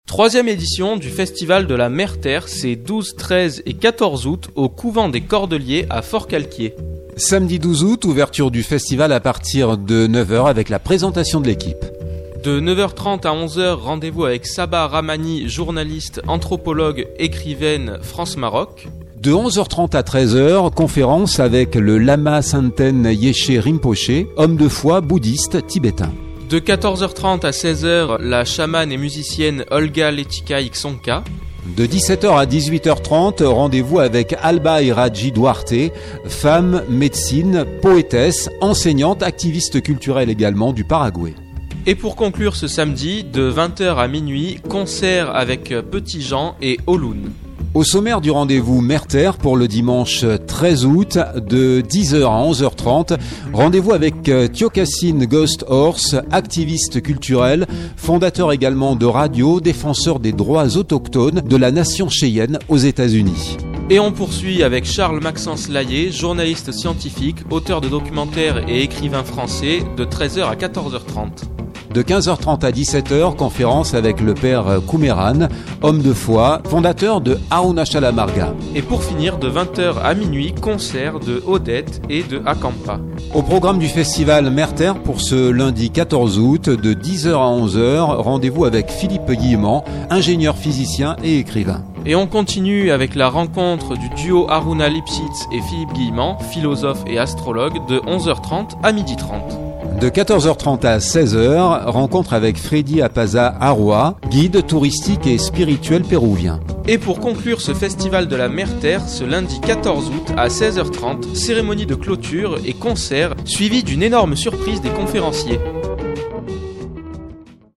ANNONCE MERE TERRE.mp3 (1.8 Mo)